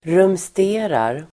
Ladda ner uttalet
Uttal: [rumst'e:rar]